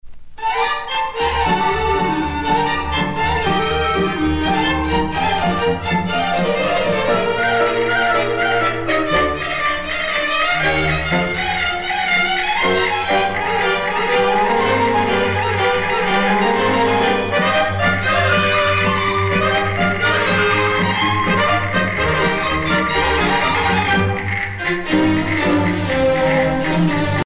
danse : paso-doble